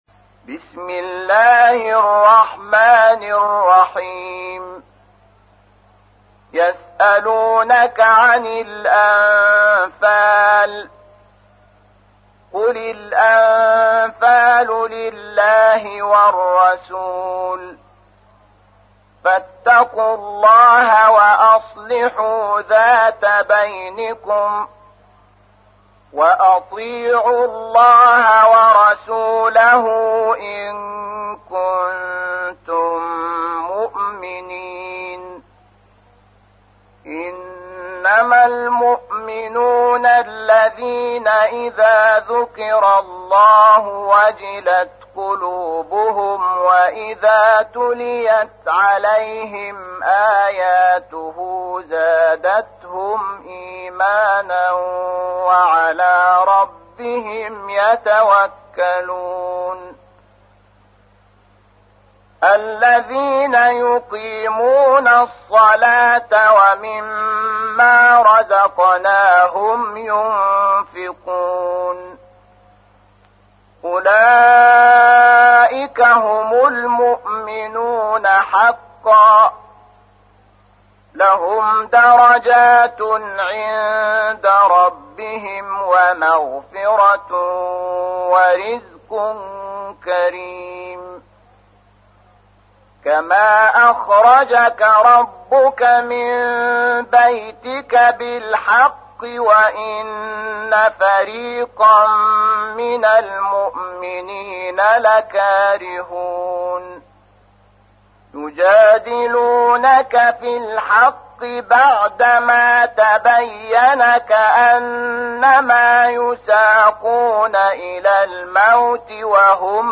تحميل : 8. سورة الأنفال / القارئ شحات محمد انور / القرآن الكريم / موقع يا حسين